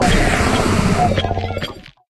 Cri d'Épine-de-Fer dans Pokémon HOME.